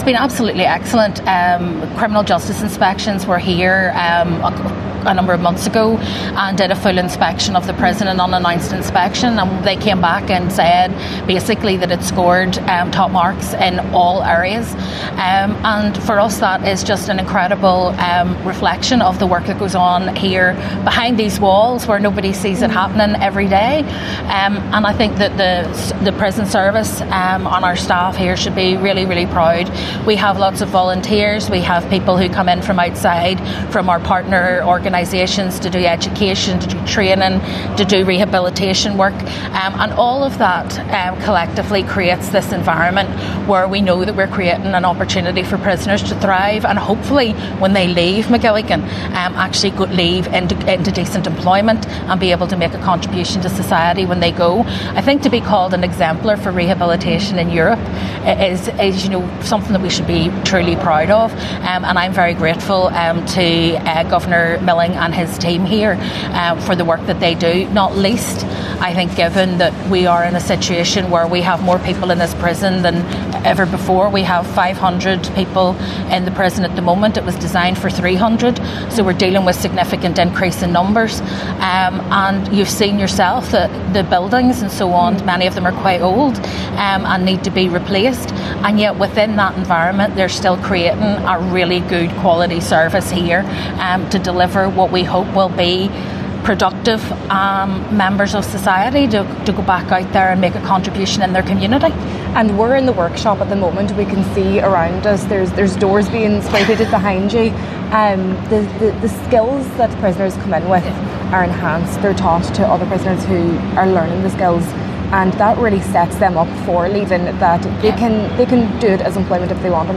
Justice Minister Naomi Long has committed to supporting these efforts: